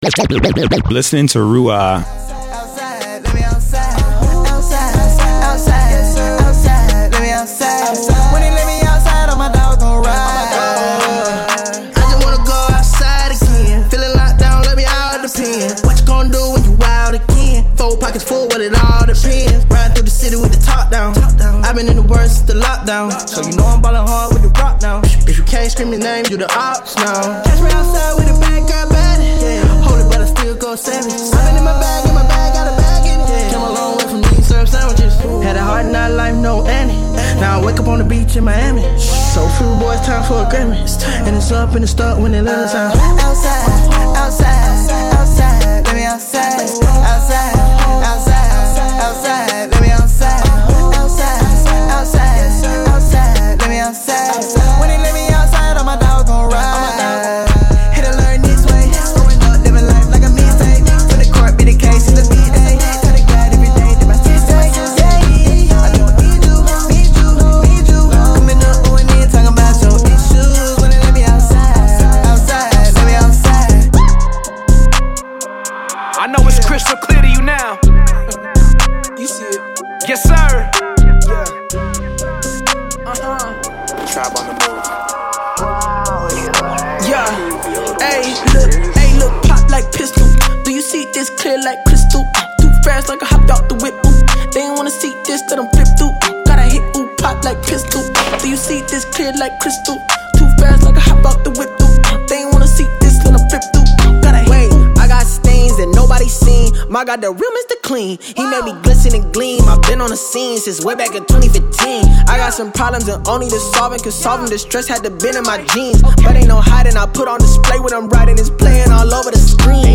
Hip-Hop Mix Demo #1